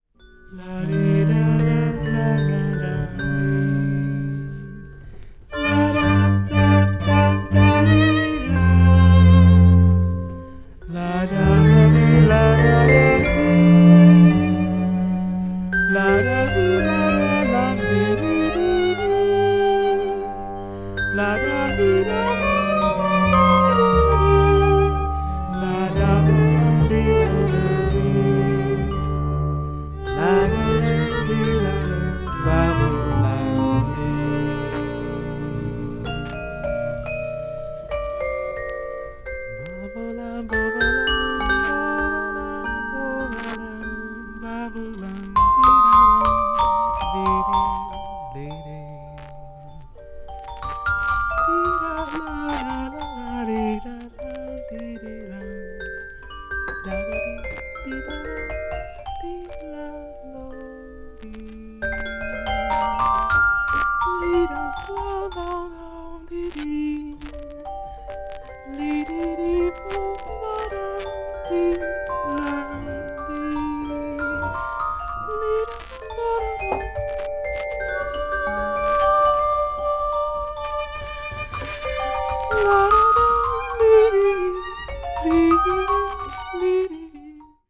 A1でプレス時の問題と思われるビリつきノイズが一部入ります。
avant-jazz   free improvisaton   free jazz